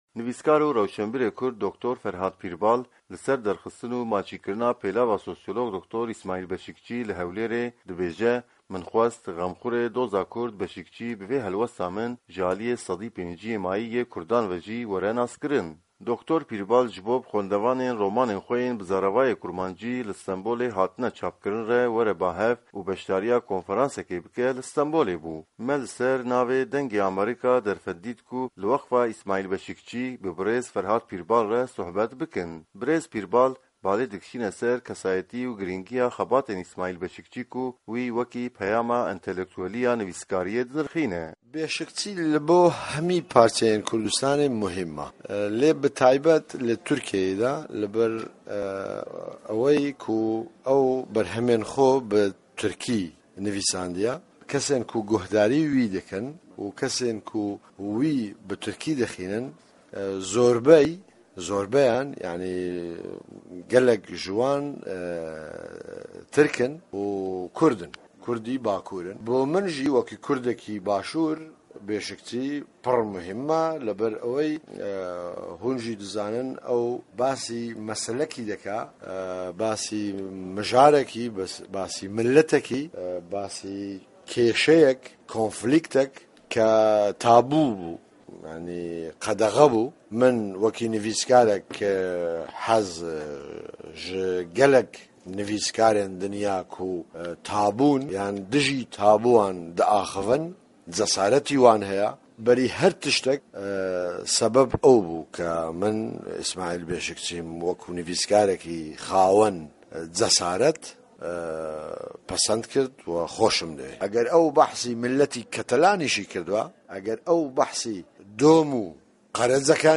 Hevpeyvîn bi Dr. Ferhad Pîrbal re